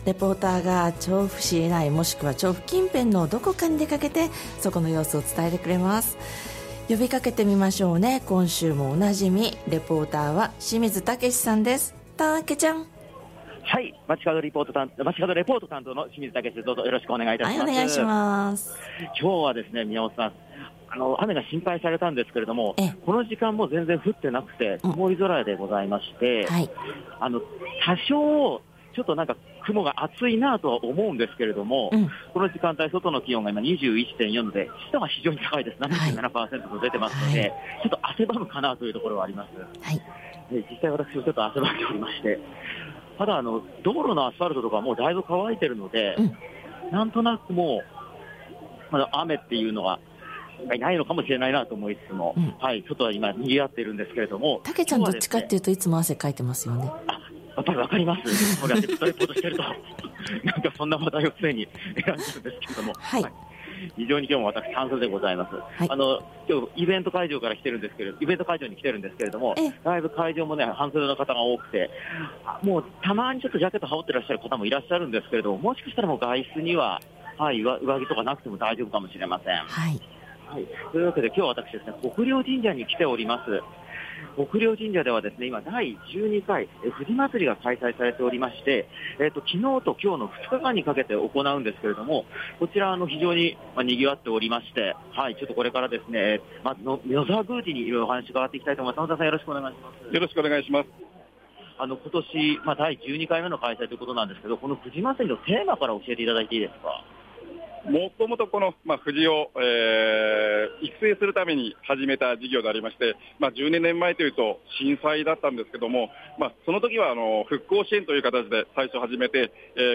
予報に反して雨に見舞われなかった空の下から、お届けした本日の街角レポートは、
國領神社で行われている「藤まつり」の会場からのレポートです！！